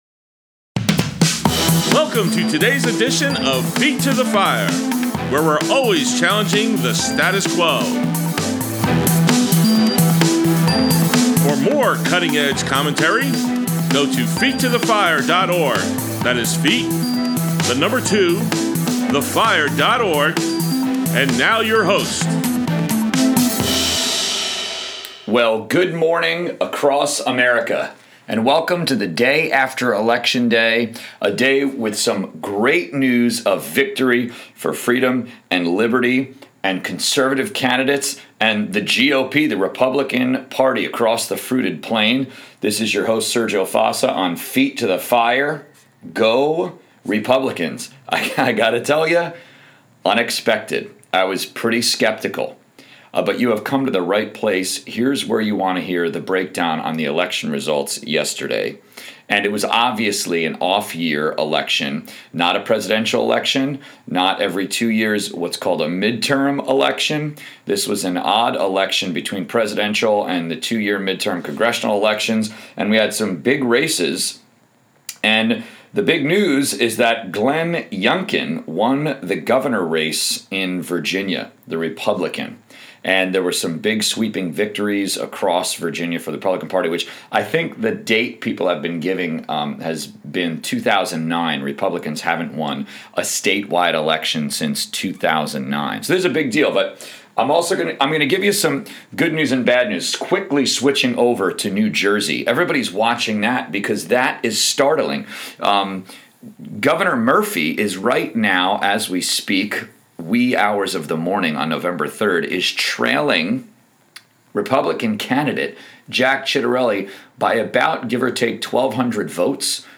| Feet to the Fire Politics: Conservative Talk Show